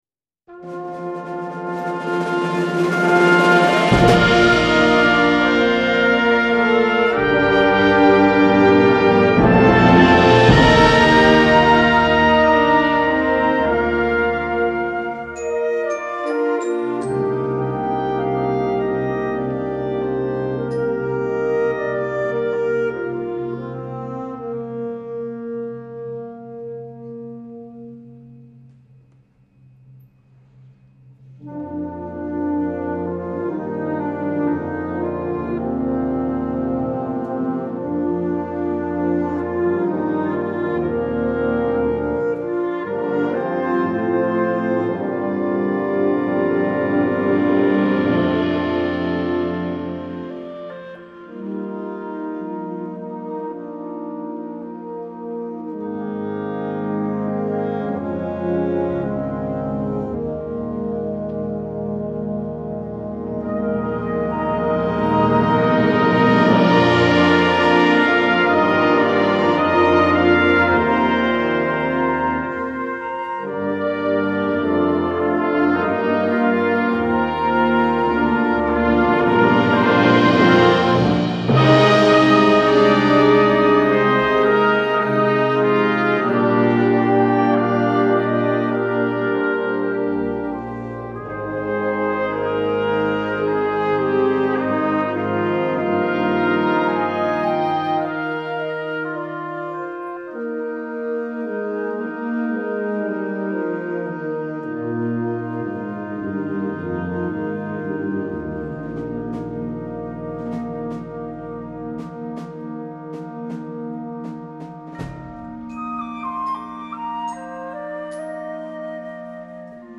Concert Band